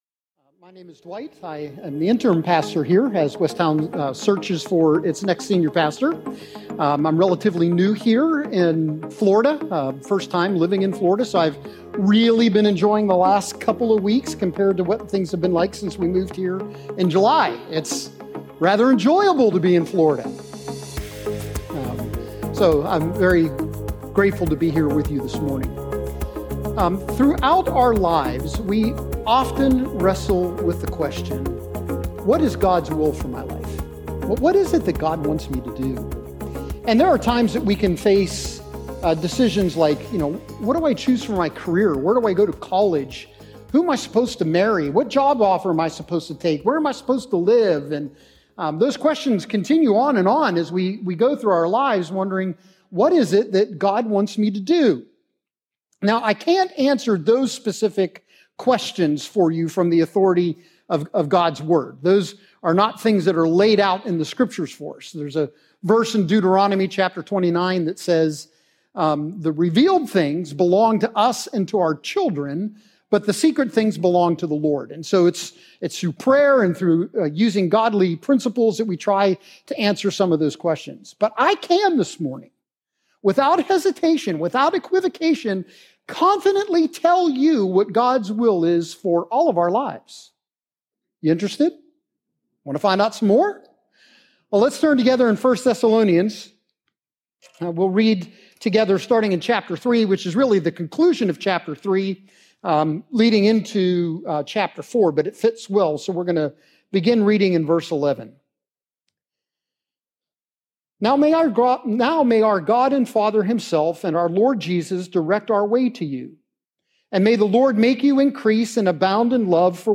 This week’s sermon addresses our relationship to sex.